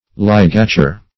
Ligature \Lig"a*ture\ (l[i^]g"[.a]*t[-u]r), v. t. (Surg.)